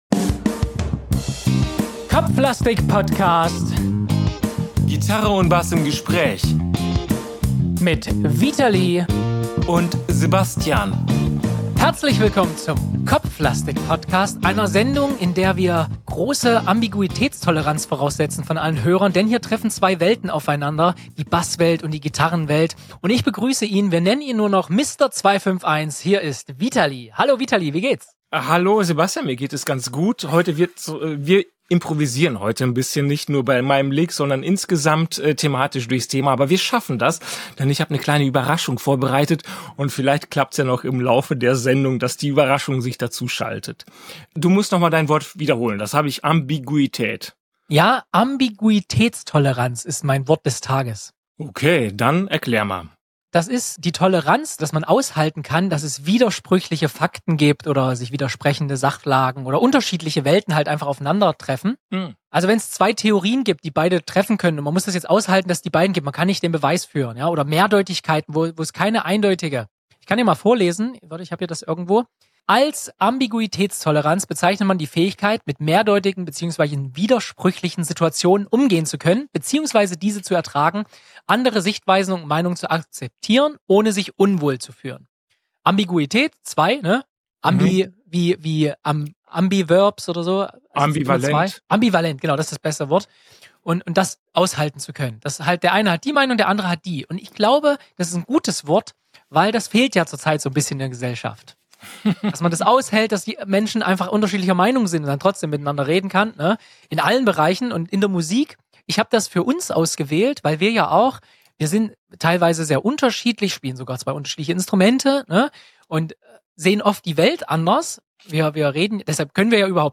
Also noch einmal, aber diesmal langsamer und bewusster. Worauf kommt es beim Erarbeiten eines Fingersatzes an?